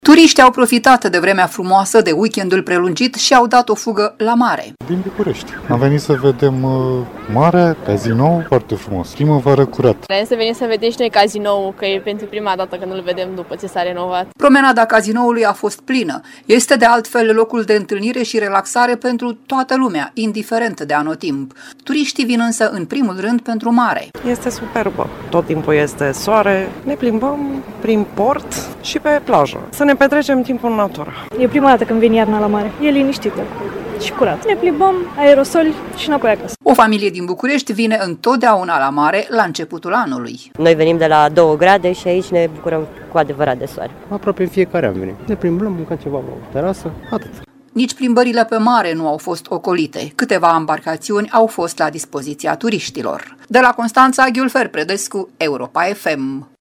Reportaj: Faleza Cazinoului din Constanța plină de turiști în a patra zi a anului / AUDIO